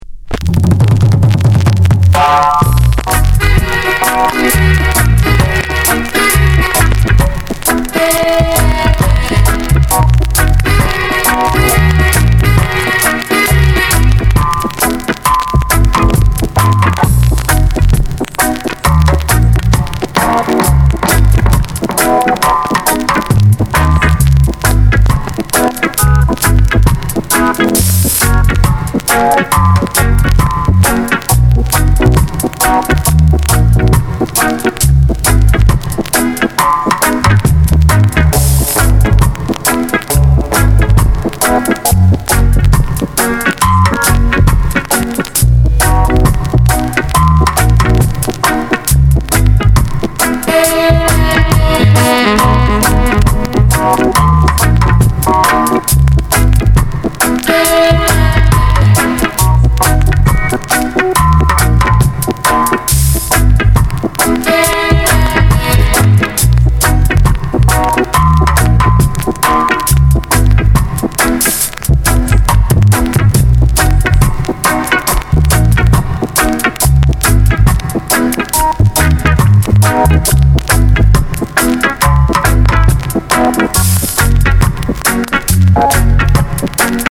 Genre: Reggae/ Lovers Rock